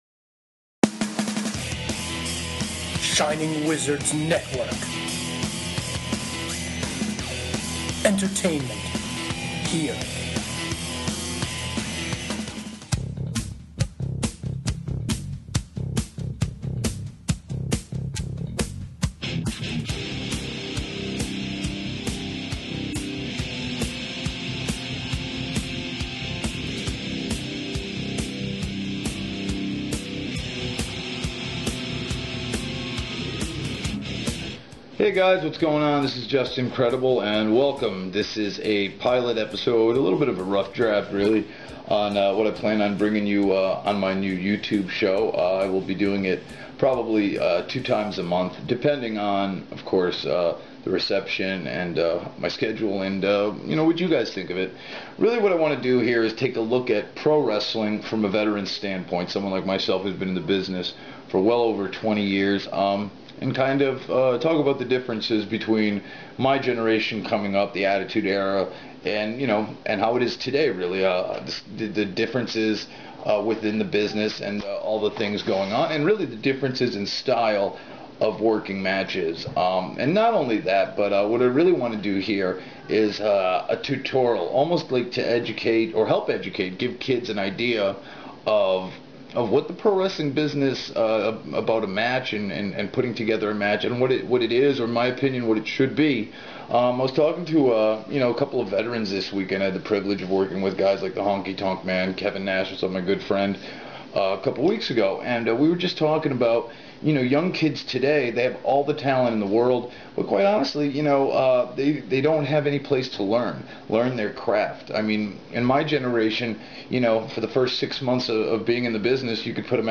A show about the ins and outs of pro wrestling. Hosted by 20 year veteran of pro wrestling Hardcore Icon Justin Credible.